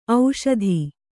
♪ auṣadhi